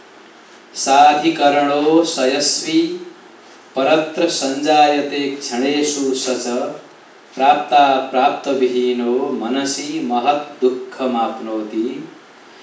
āryā